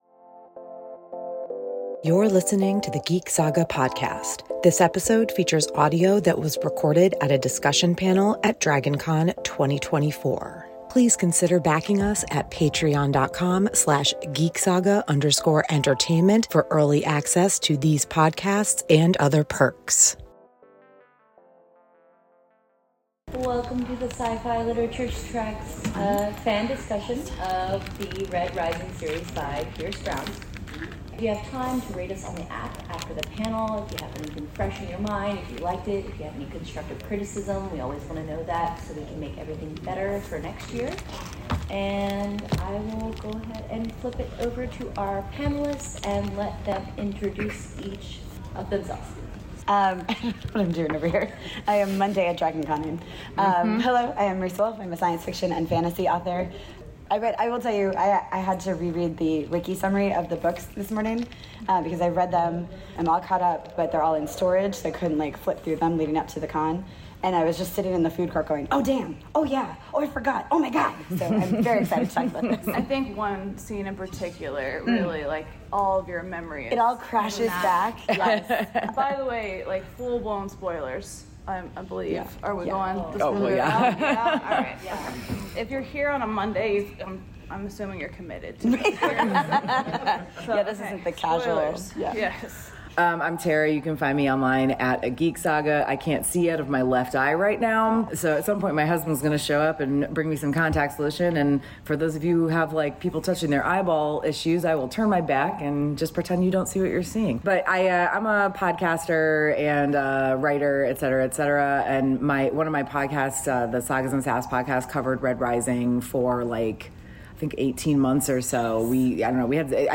PODCAST-Red-Rising-Saga-Dragon-Con-2024.mp3